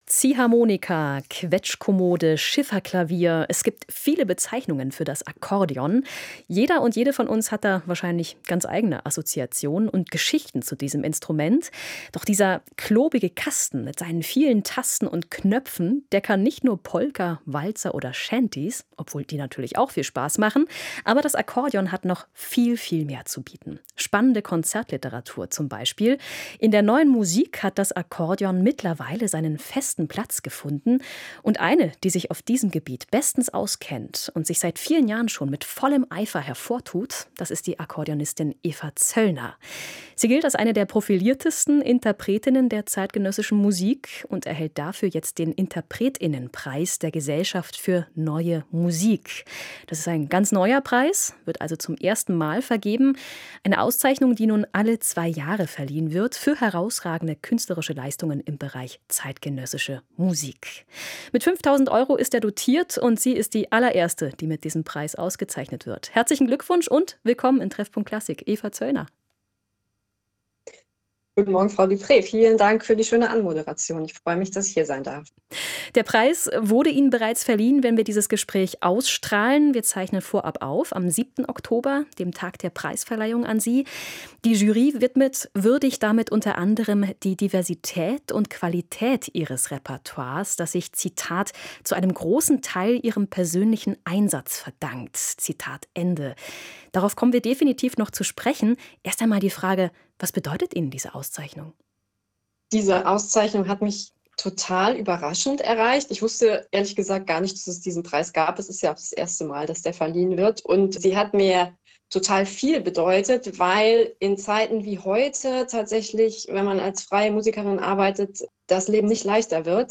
Was ihr der Preis bedeutet und warum sich das Akkorden besonders gut für zeitgenössische Musik eignet, erzählt sie im SWR Kultur Musikgespräch.